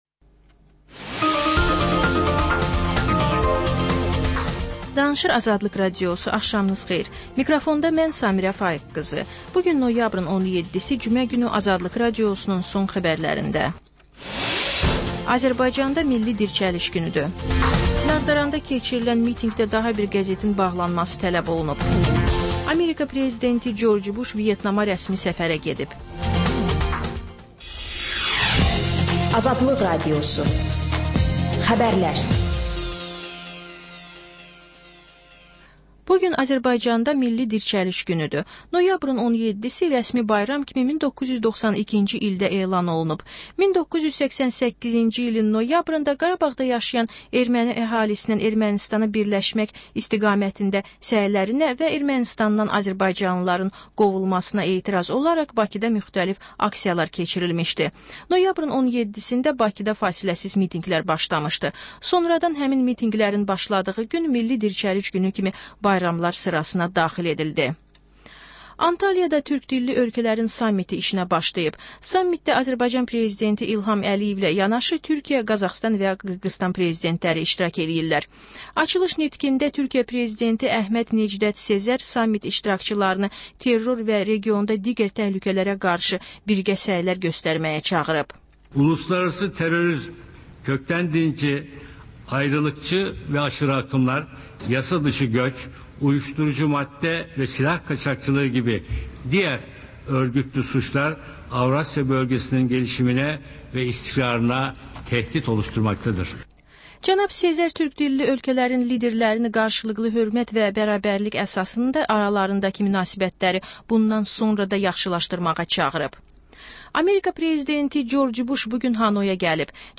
Xəbərlər, reportajlar, müsahibələr. Və sonda: Qlobus: Xaricdə yaşayan azərbaycanlılar barədə xüsusi veriliş.